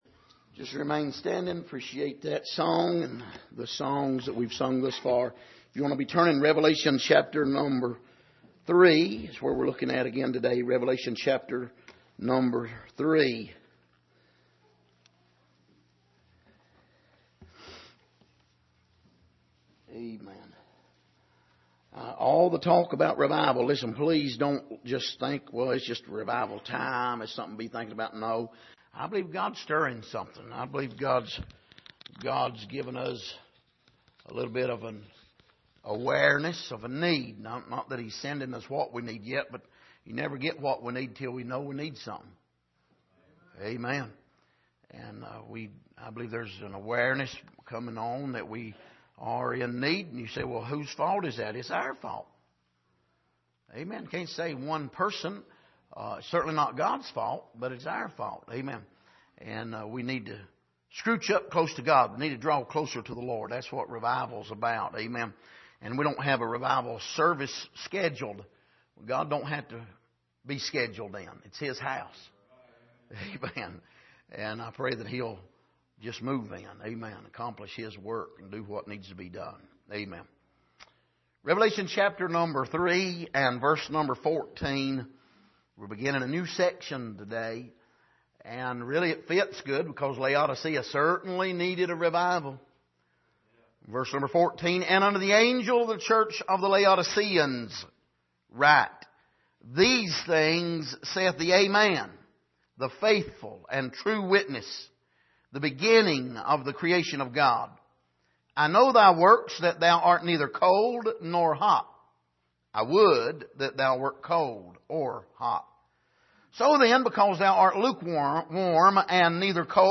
Passage: Revelation 3:14-22 Service: Sunday Morning